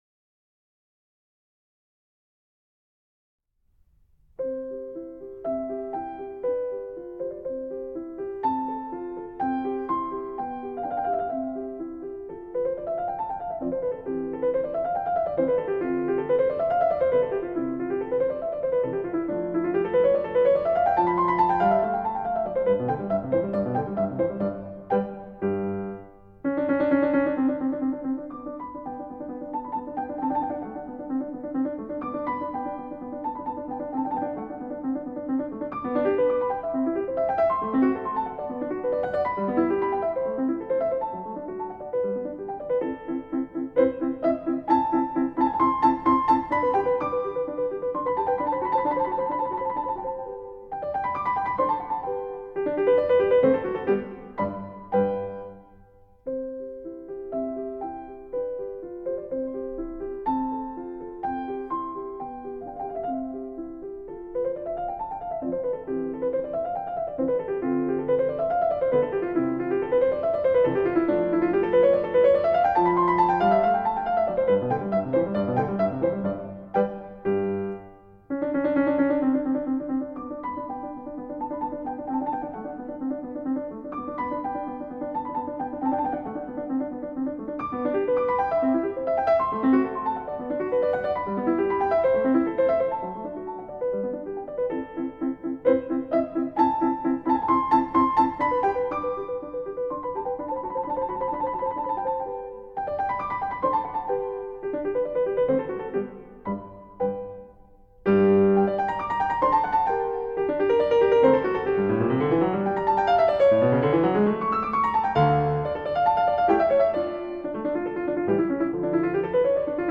“Piano Sonata No. 16 in C Major”, K. 545 I. Allegro by Wolfgang Amadeus Mozart, performed by Mitsuko Uchida:
mozart-piano-sonata-no.-16-in-c-major-k.-545-sonata-facile-i.-allegro.mp3